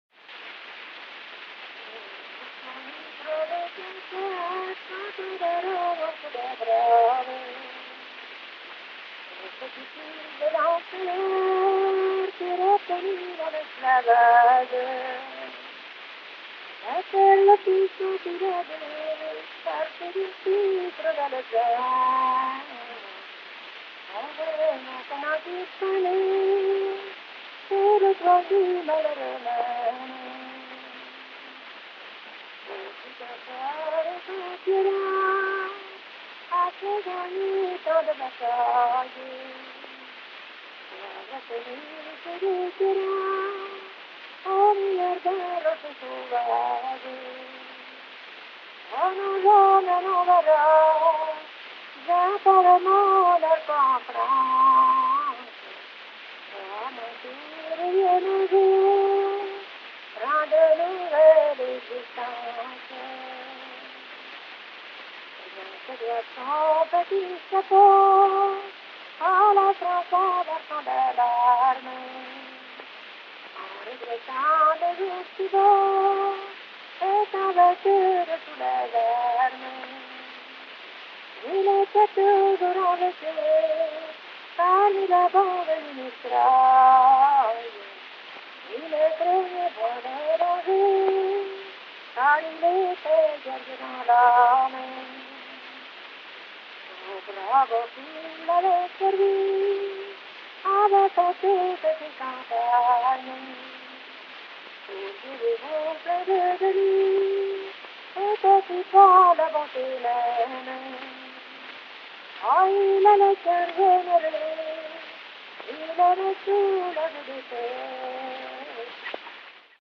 Ce sera à La Châtre le 28 juin 1913, Nohant-Vic le 29 juin et Saint-Chartier le 30 juin
allons…et Briolée aux boeufs
cultivateur]et  chant.